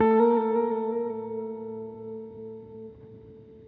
guitar_jazz.wav